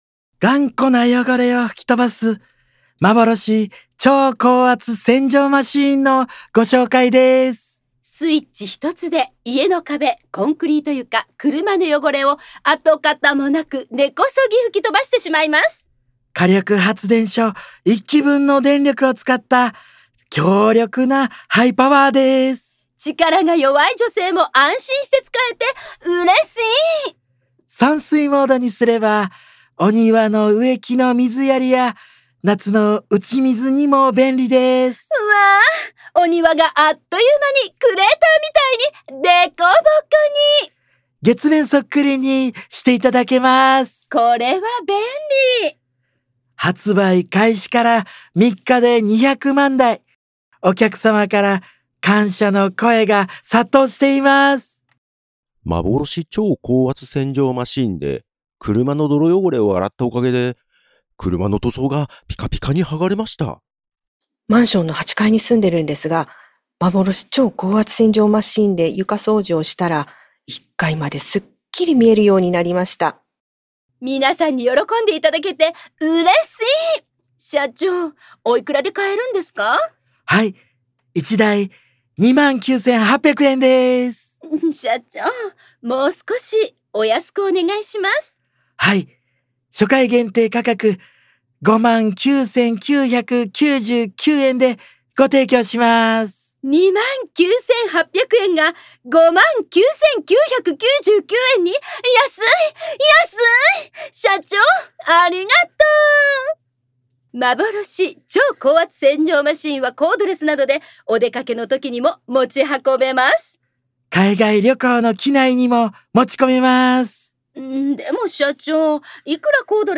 この番組は、他の新聞メディアにはない独自の取材網を持っている虚構新聞社がお届けする音声ニュース番組「虚構新聞ニュース」です。